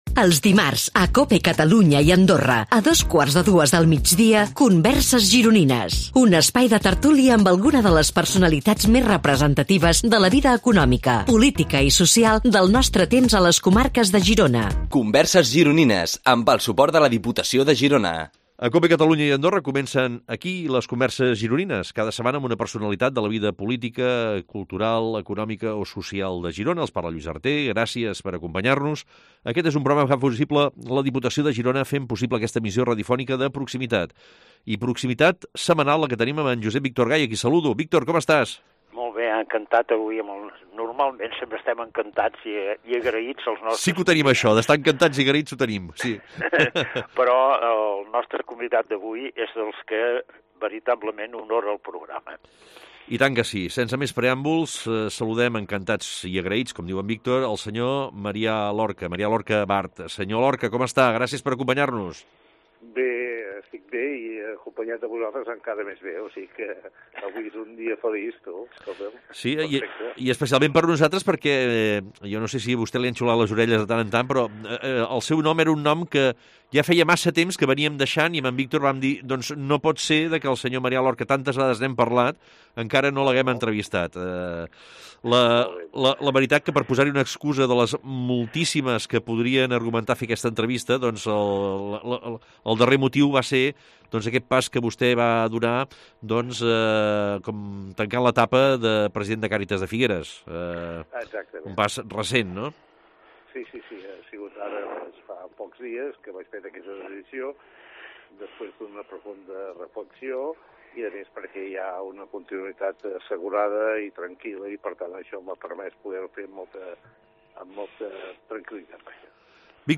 Aquestes converses es creen en un format de tertúlia en el que en un clima distès i relaxat els convidats ens sorprenen pels seus coneixements i pel relat de les seves trajectòries. Actualment el programa s’enregistra i emet en els estudis de la Cadena Cope a Girona, situats en el carrer de la Sèquia número tres de Girona, just al costat del museu del Cinema.